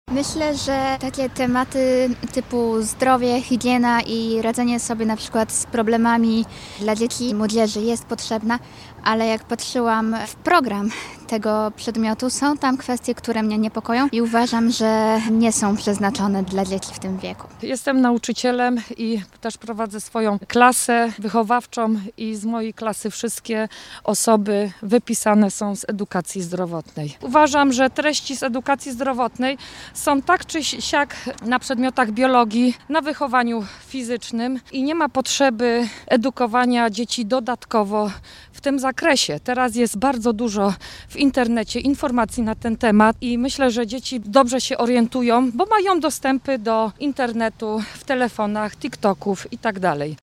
Zapytaliśmy, co na ten temat myślą wrocławianie (również rodzice).